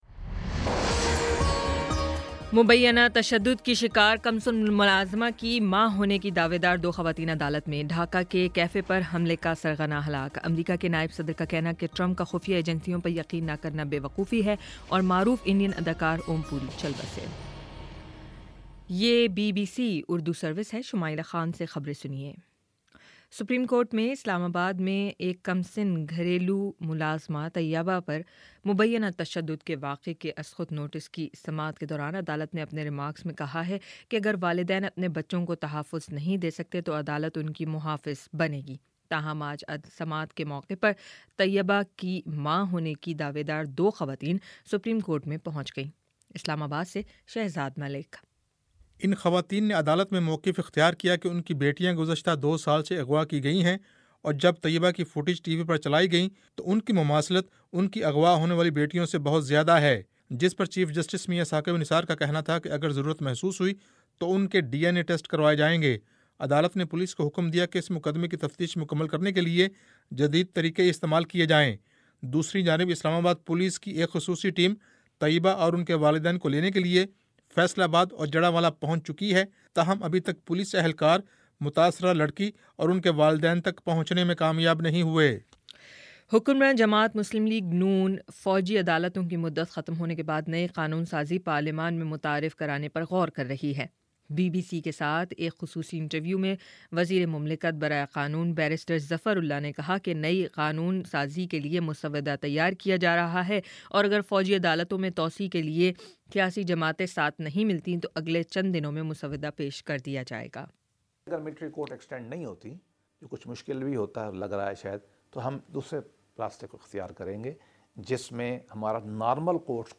جنوری 06 : شام پانچ بجے کا نیوز بُلیٹن